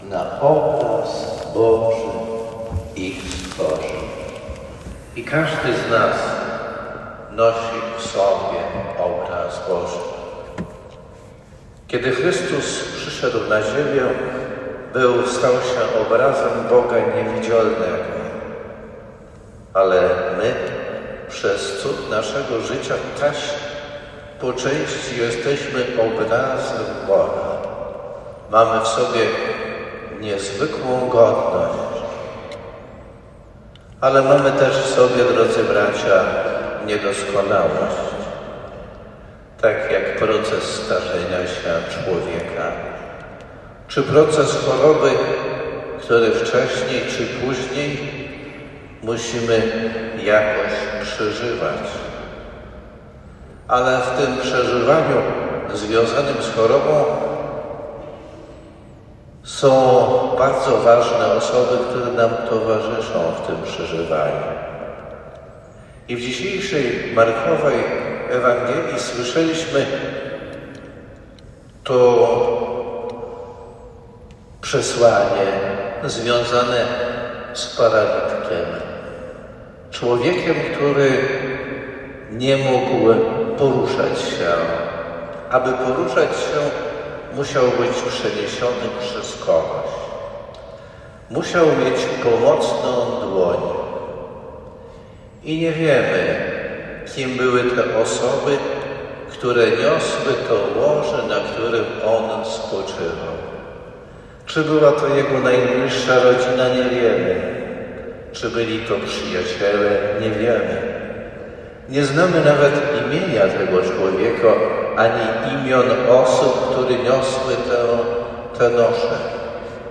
Jednym z kościołów stacyjnych w naszej diecezji jest łomżyńska Katedra p.w. św. Michała Archanioła, w której w intencji wszystkich chorych i ich opiekunów modlił się dzisiaj ksiądz biskup Janusz Stepnowski.